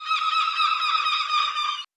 Seagull Scared 001.wav